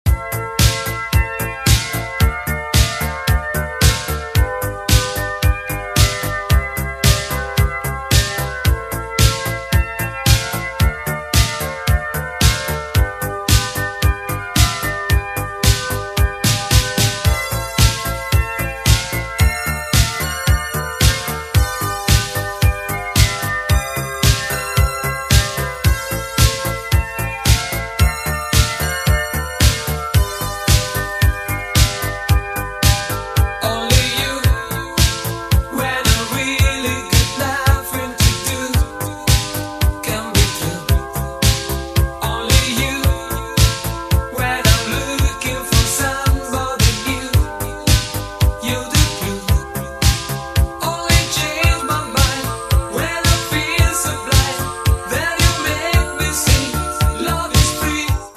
golden 80s vibes